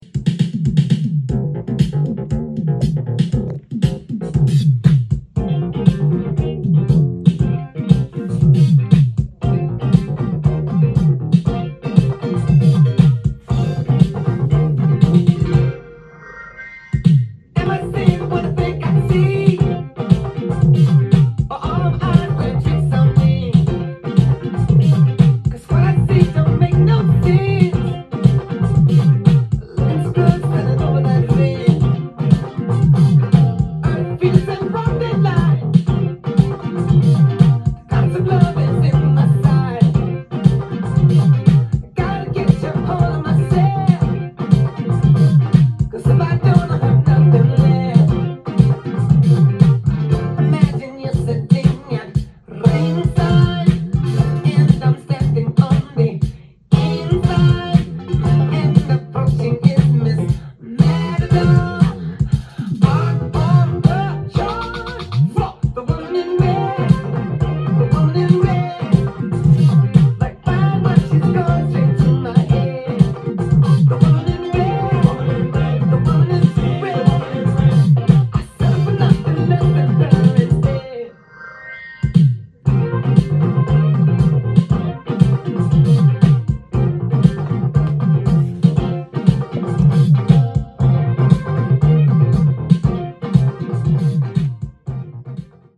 ジャンル：SOUL
店頭で録音した音源の為、多少の外部音や音質の悪さはございますが、サンプルとしてご視聴ください。
音が稀にチリ・プツ出る程度